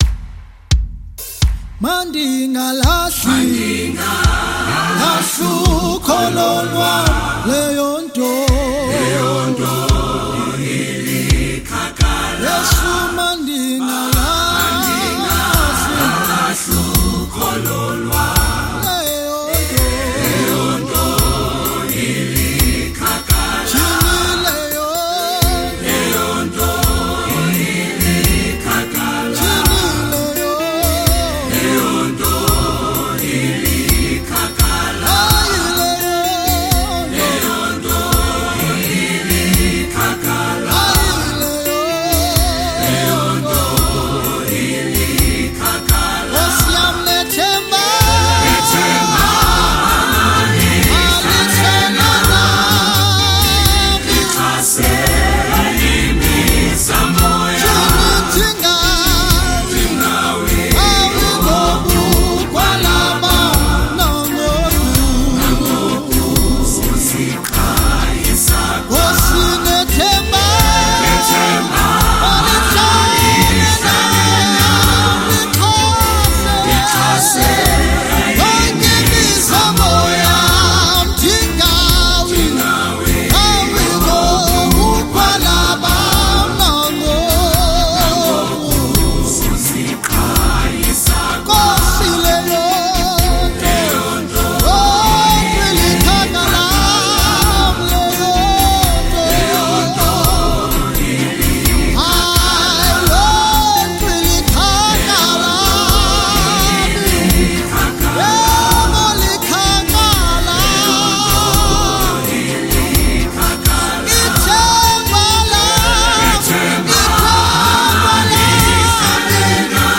South African Gospel Music Group